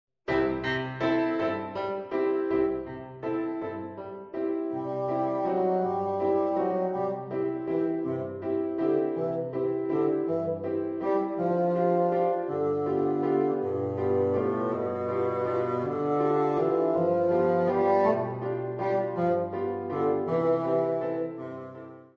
Bassoon and Piano